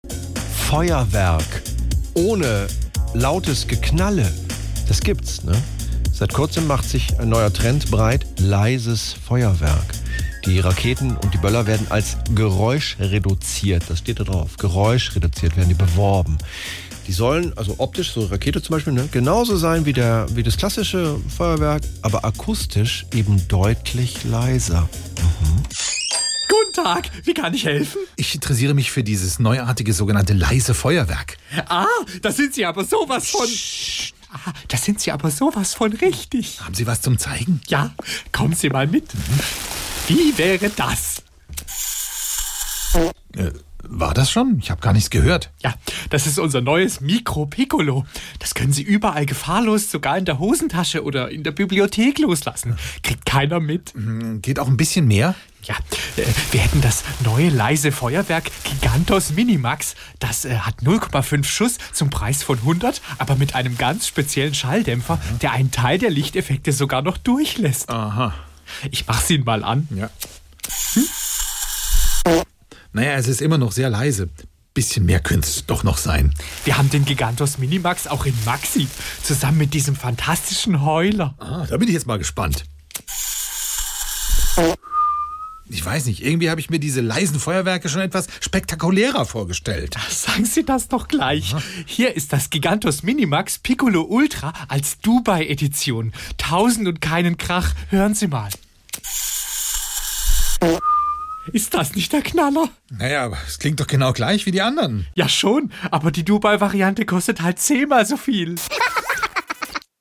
SWR3 Comedy Leises Feuerwerk im Trend